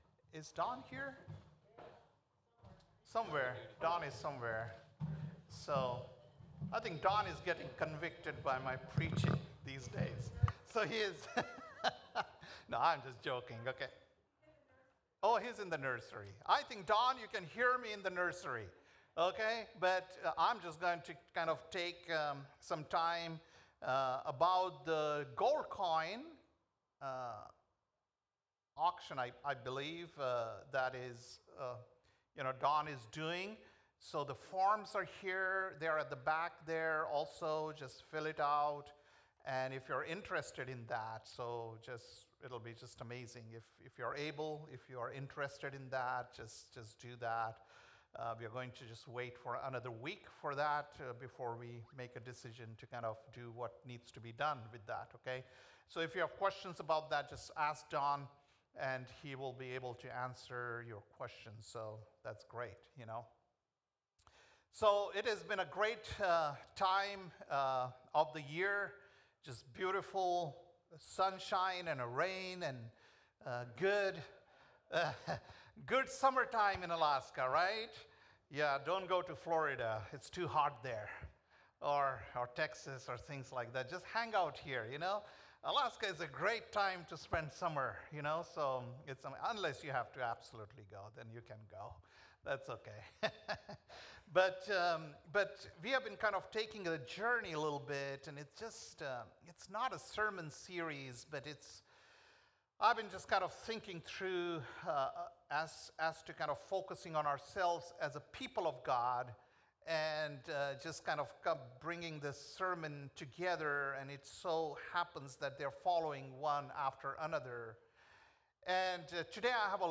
June 8th, 2025 - Sunday Service - Wasilla Lake Church